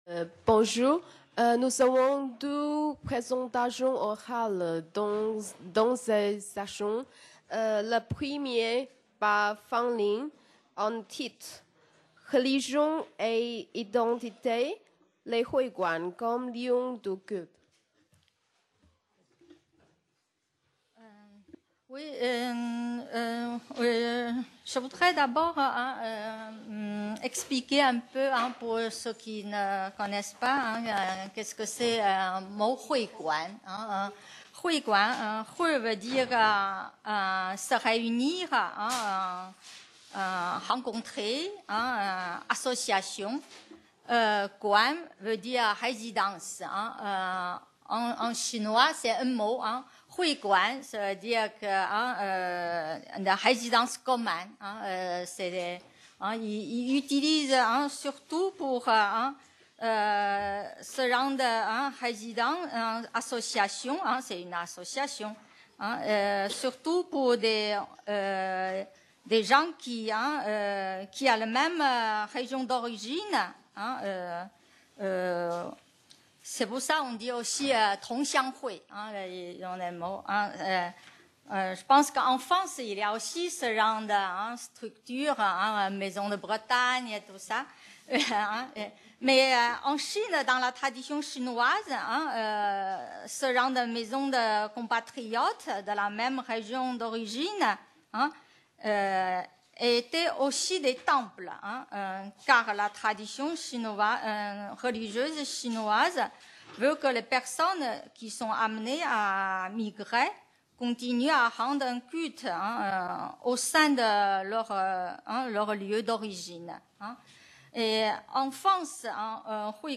Colloque international du 18 au 20 mai 2016 au CNRS site Pouchet, Paris 17e et à l'INALCO, Paris 13e.